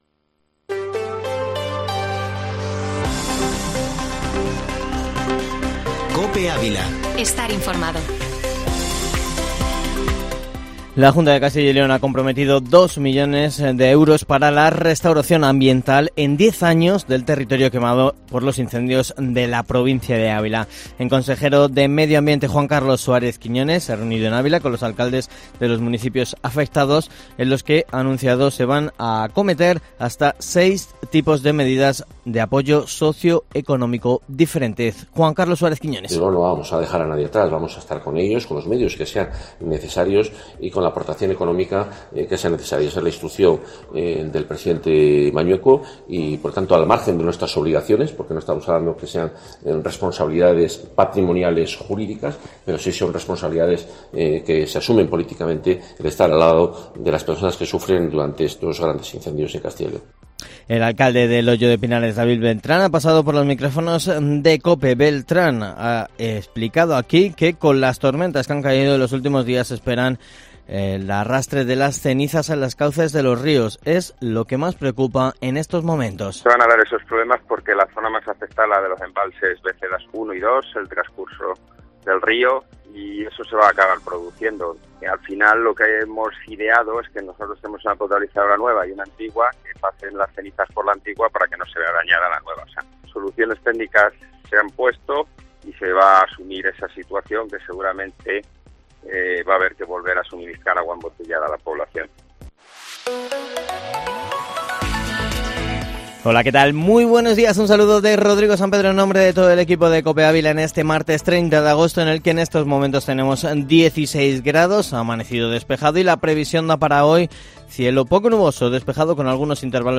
Informativo Matinal Herrera en COPE Ávila -30-agosoto